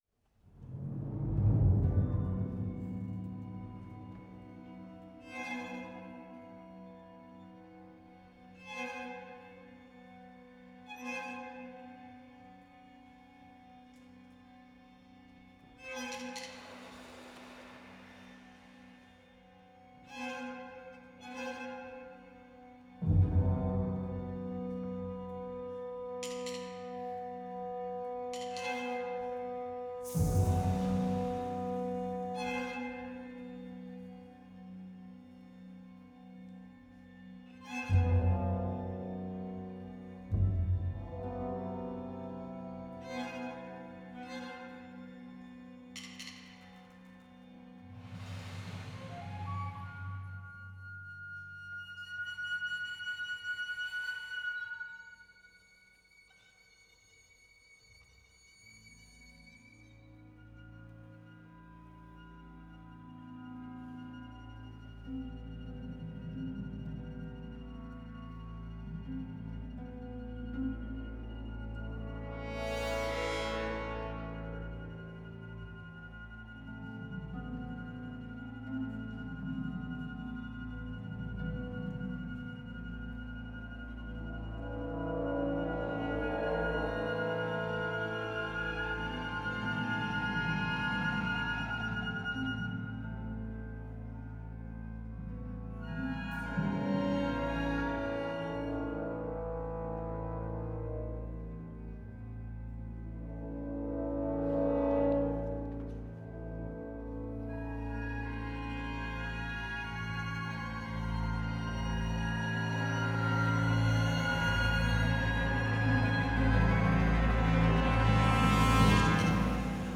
for orchestra